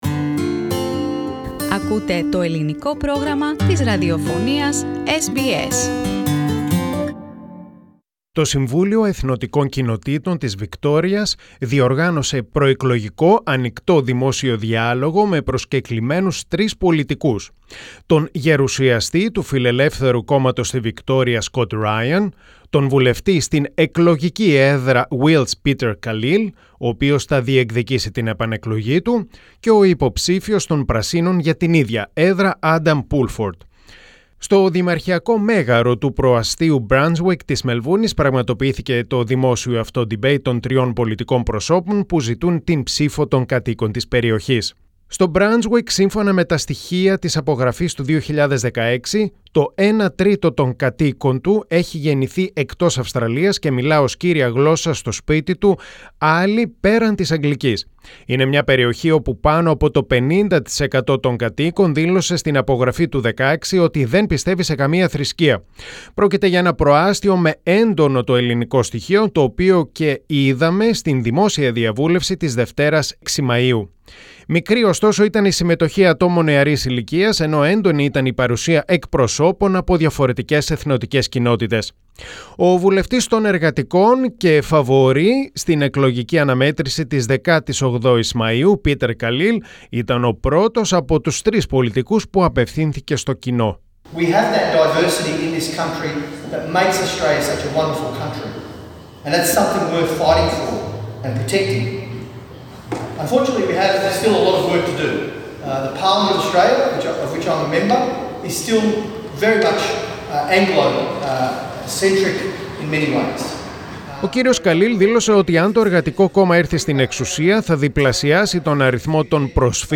A vibrant election forum at Melbourne's multicultural suburb of Brunswick
Ethnic Communities' Council of Victoria, in partnership with the Federation of Ethnic Communities Councils of Australia and Greek Australian non-profit community services organization "Pronia" hosted a “Meet Your Candidates” community forum on Monday 6 May at Brunswick Town Hall.